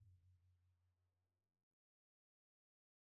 Percussion / Timpani
Timpani1_Hit_v1_rr1_Sum.wav